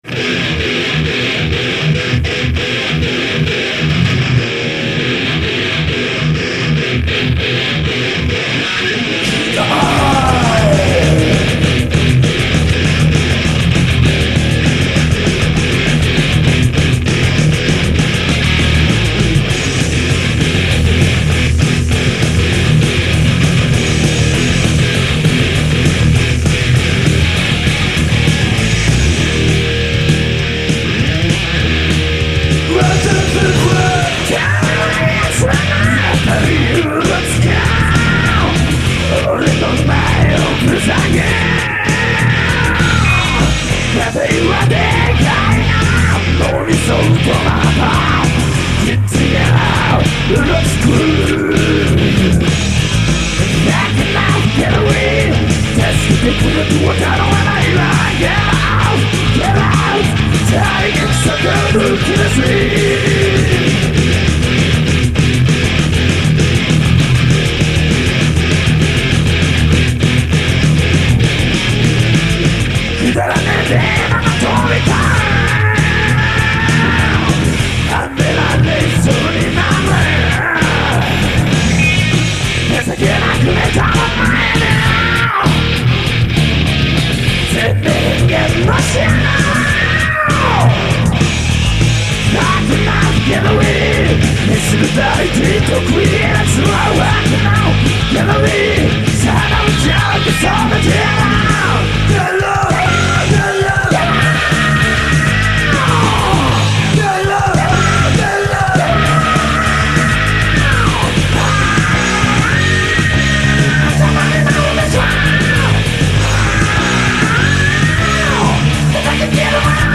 HARD ROCK系
＊ボリューム注意
こちらもドラムはチープな（笑）リズムマシーンで打ち込み。4トラによる宅録、DEMO TAPEより。